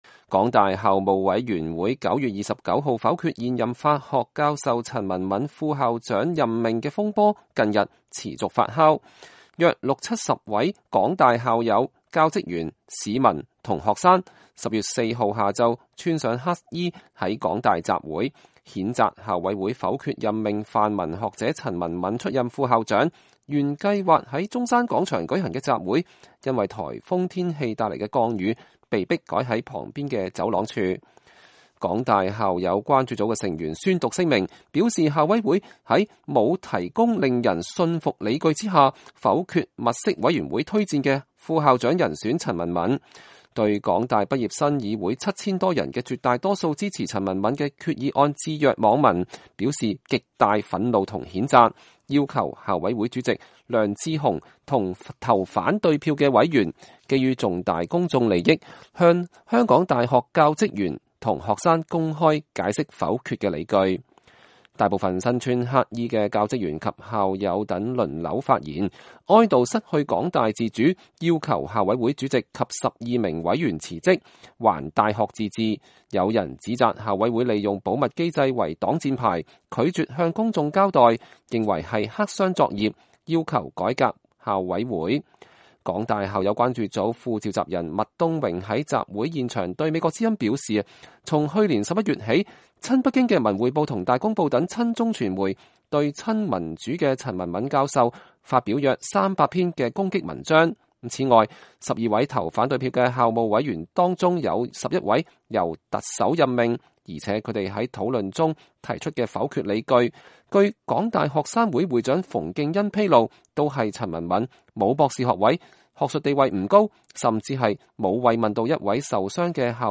原定計劃在中山廣場舉行的集會，因颱風天氣帶來的降雨，被迫改在旁邊的走廊處。